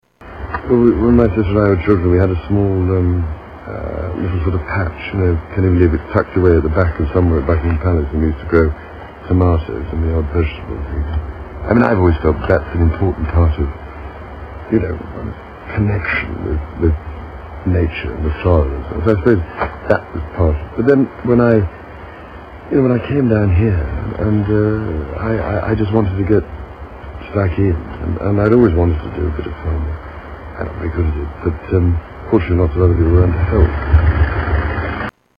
Prince Charles talks about nature